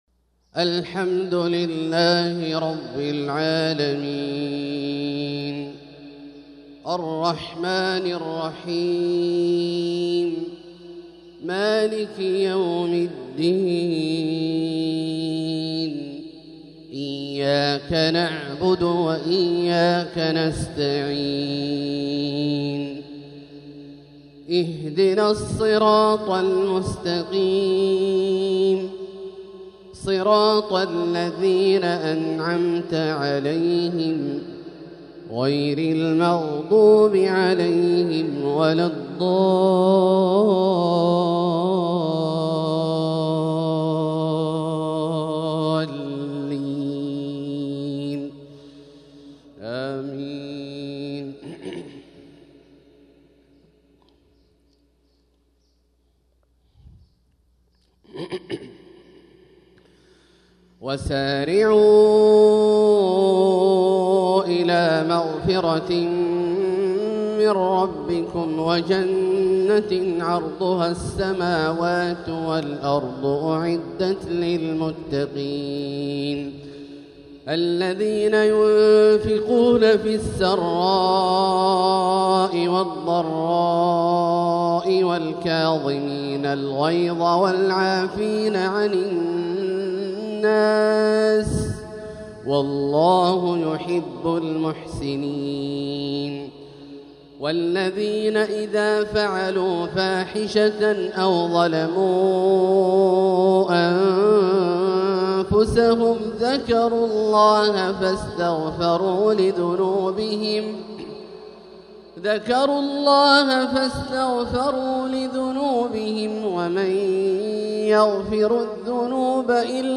تلاوة طيبة من سورة آل عمران | فجر الخميس 9-7-1446هـ > ١٤٤٦ هـ > الفروض - تلاوات عبدالله الجهني